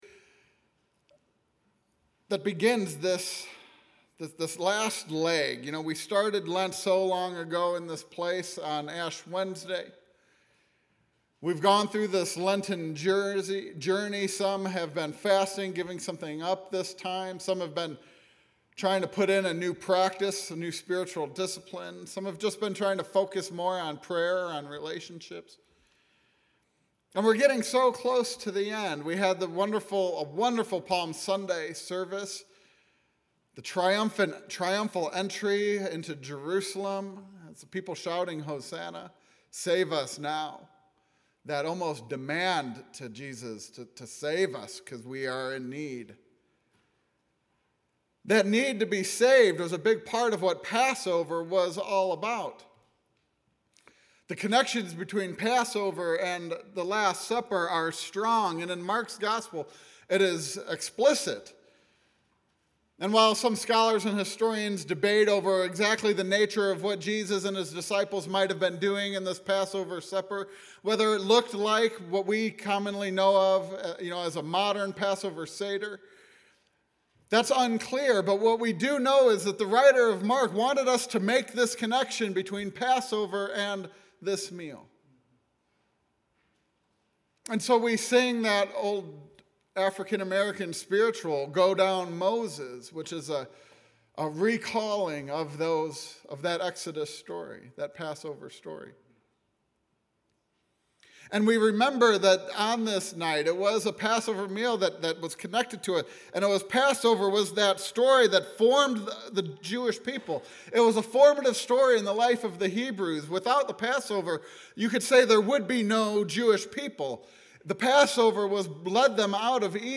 Sermon: Jesus saved a seat
This was my Maundy Thursday sermon this year.